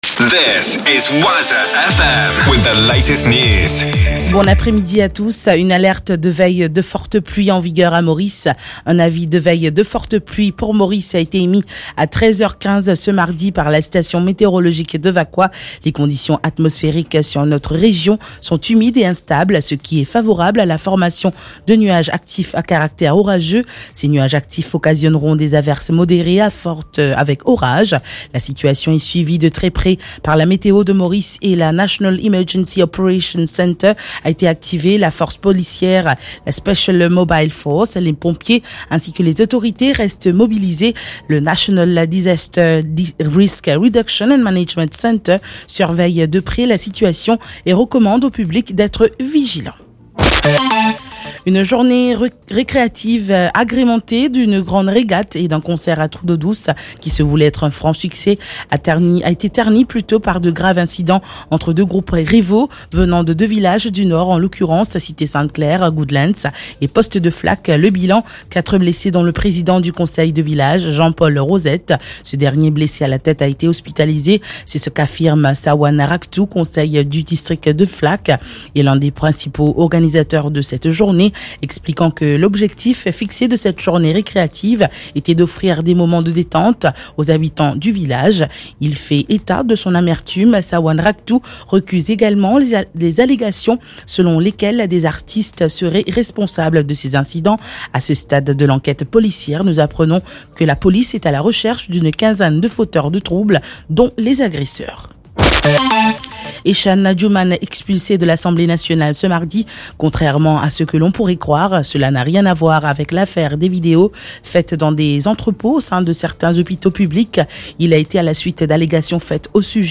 NEWS 15H - 7.11.23